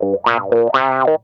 ITCH RIFF 1.wav